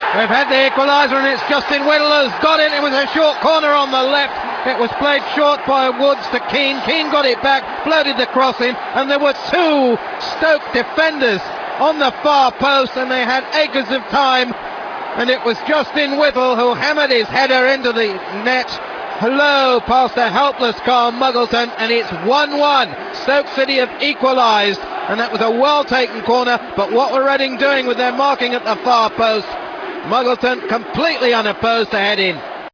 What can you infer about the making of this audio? Match Audio